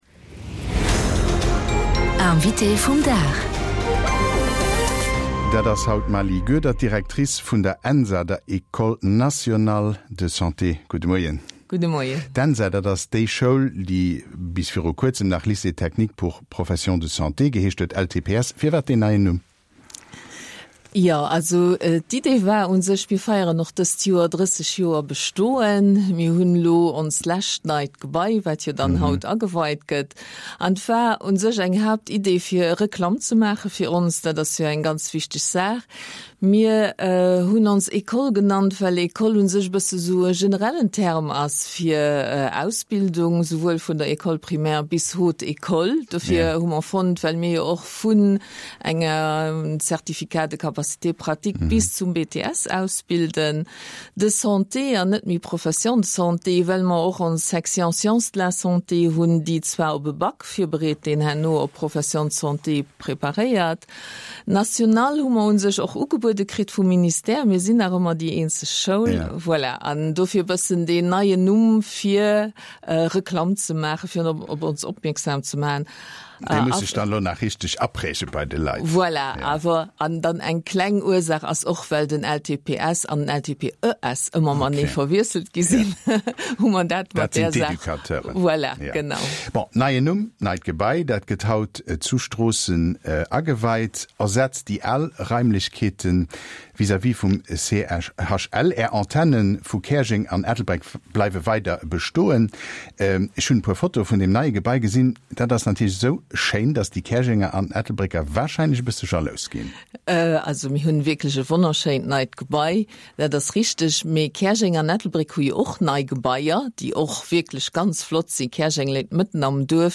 Invitéen am Mëttelpunkt vun der Aktualitéit: Mir stellen hinnen déi Froen, déi Dir Iech och stellt.